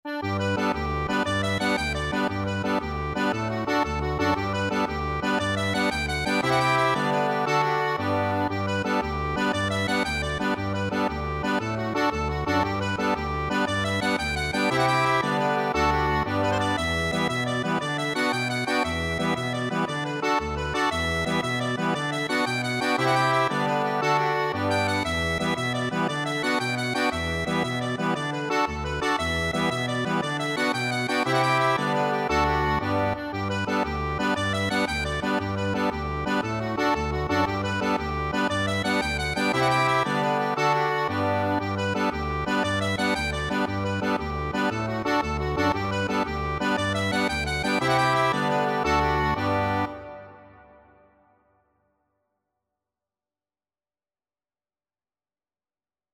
6/8 (View more 6/8 Music)
With energy .=c.116
Accordion  (View more Intermediate Accordion Music)
Irish